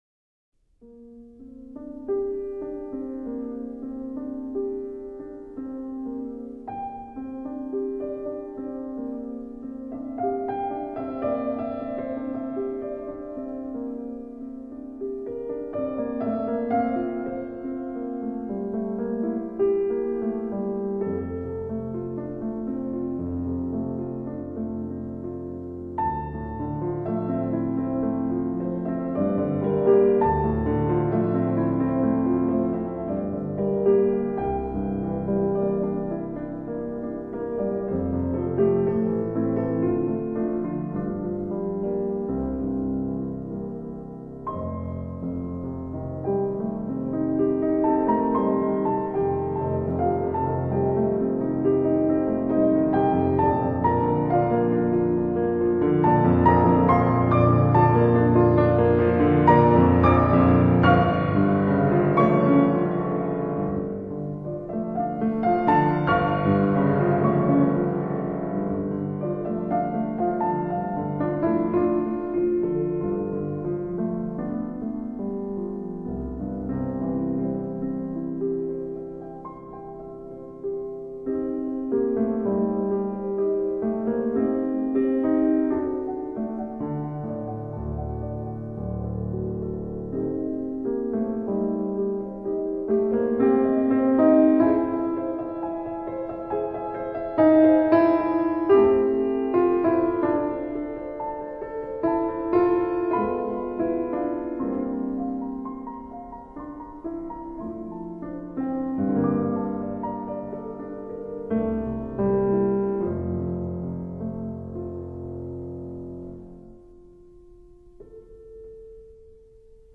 Música para relajación.